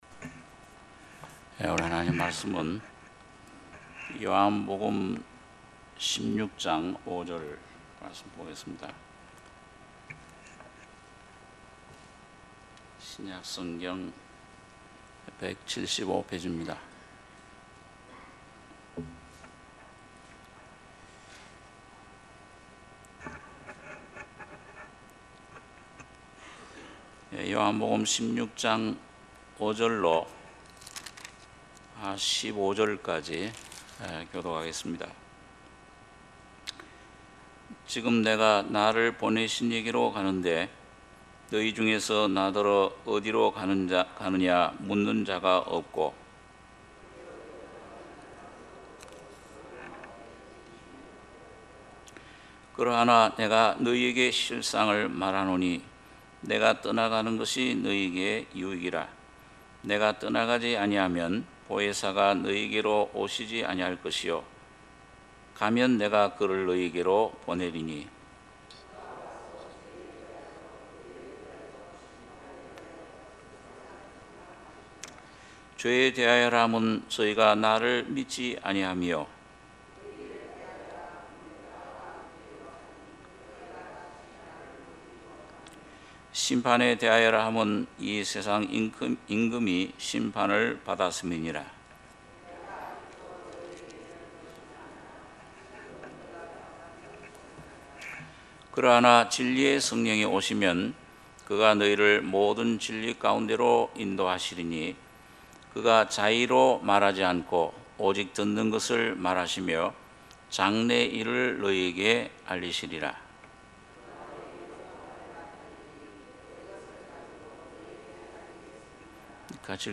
특별집회 - 요한복음 16장 5절~15절